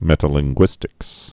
(mĕtə-lĭng-gwĭstĭks)